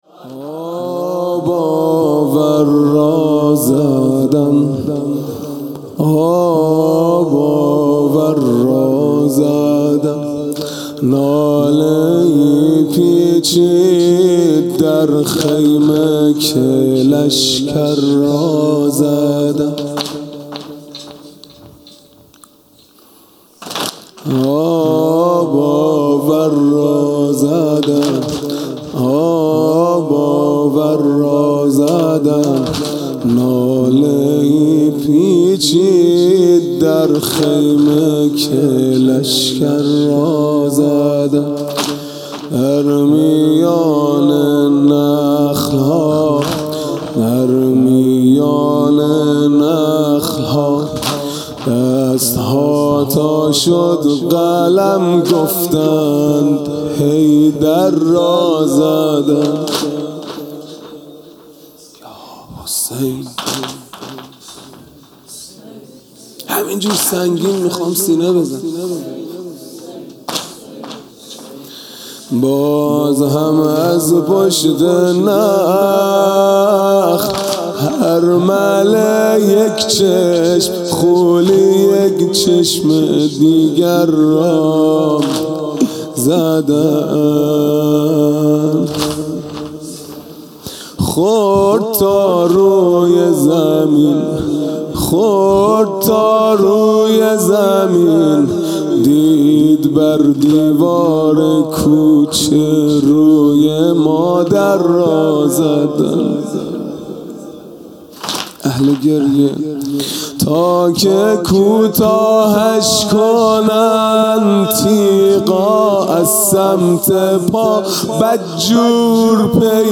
خیمه گاه - هیئت بچه های فاطمه (س) - واحد سنگین | آب آور را زدند
محرم 1441 | صبح نهم